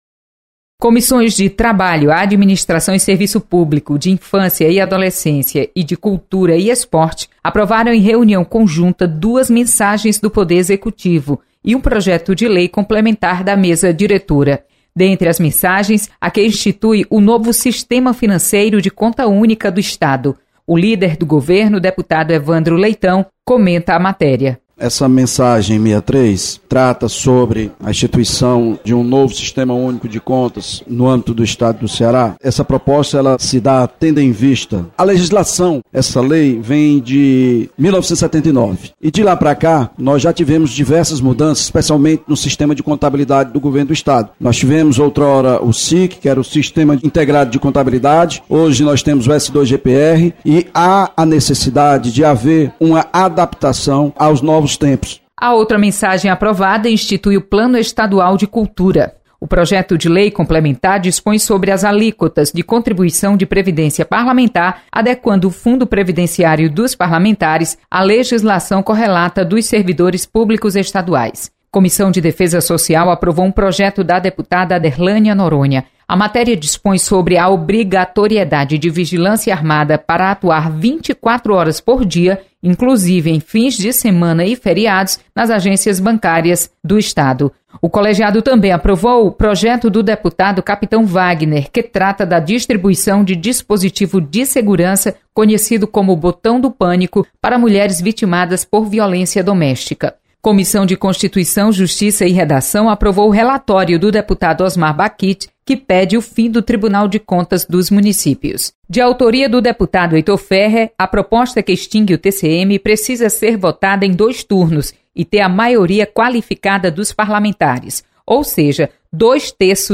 Você está aqui: Início Comunicação Rádio FM Assembleia Notícias Comissões